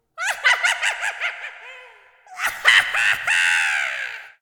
01277 witch cackle laughter 1
cackle evil laugh laughter witch sound effect free sound royalty free Funny